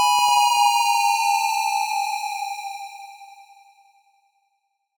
snd_closet_fall.ogg